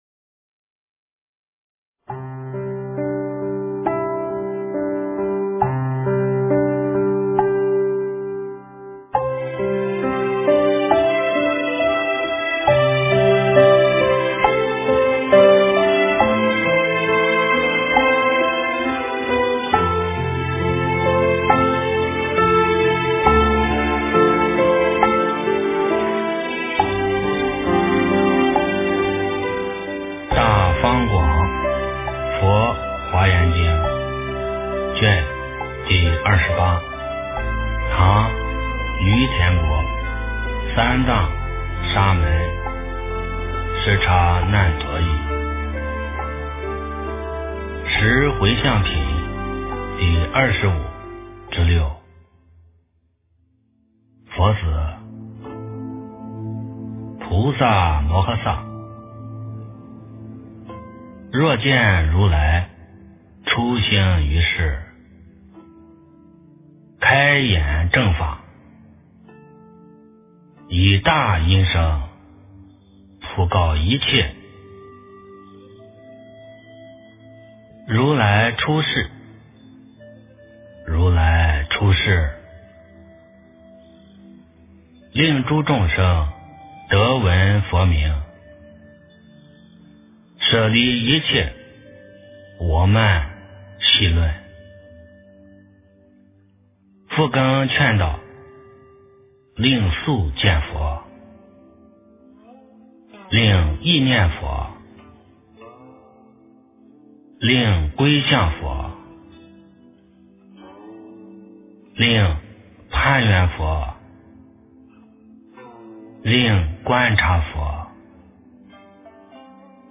《华严经》28卷 - 诵经 - 云佛论坛